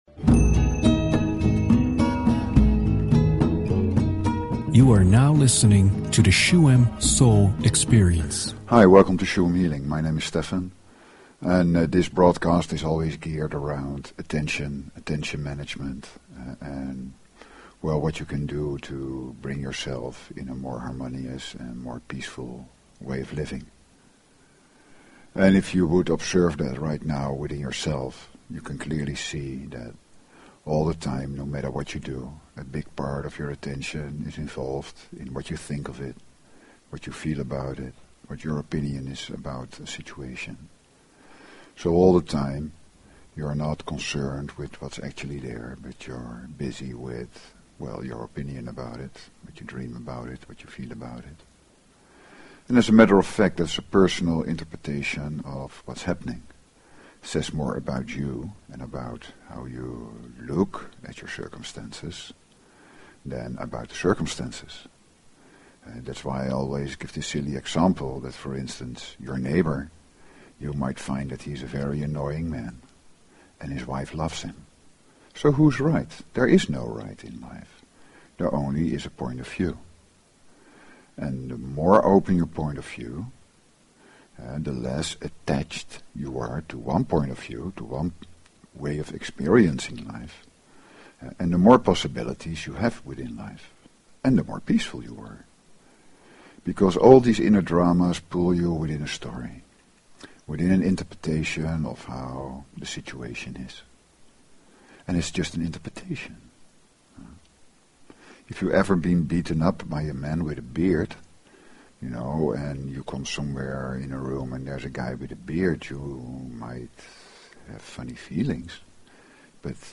Talk Show Episode, Audio Podcast, Shuem_Soul_Experience and Courtesy of BBS Radio on , show guests , about , categorized as
The shamanic healingmeditation in the second part of the show supports this process and when you let the sounds of drum, rattle and chant flow through it is as if they take away anxiety, stress and negativity without you having to do anything.